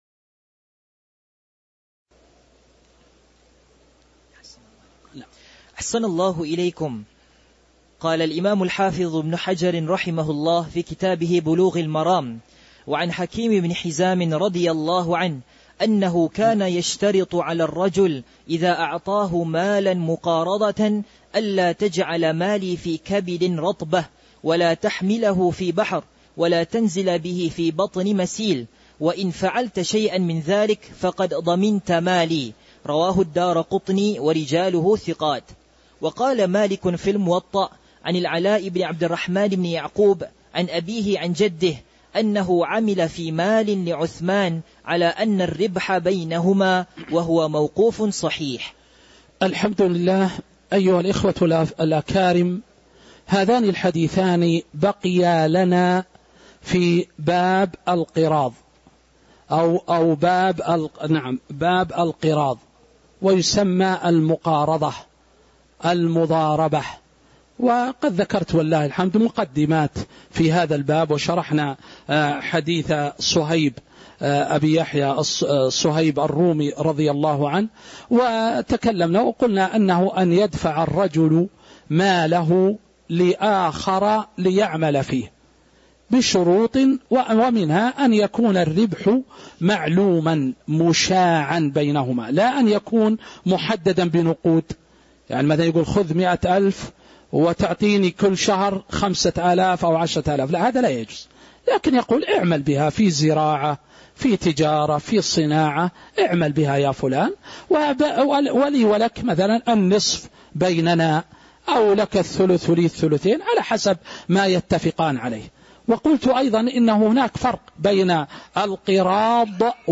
تاريخ النشر ١٣ جمادى الآخرة ١٤٤٦ هـ المكان: المسجد النبوي الشيخ